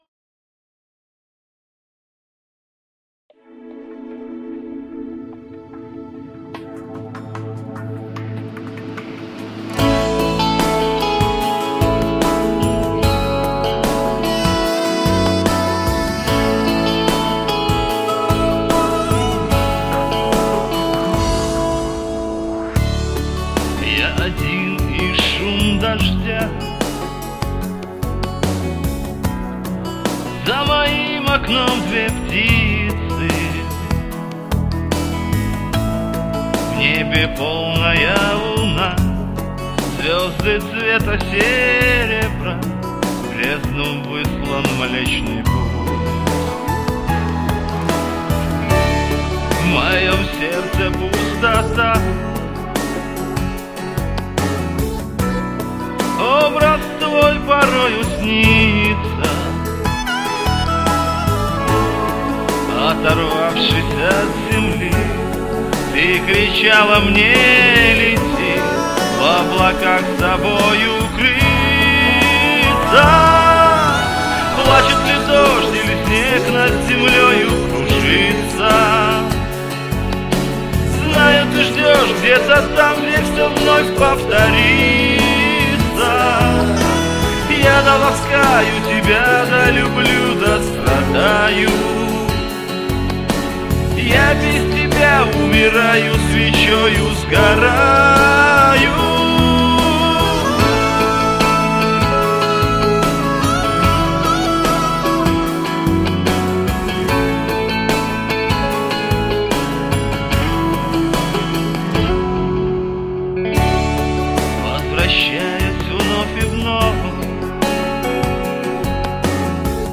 Красиво, качественно, проникновенно.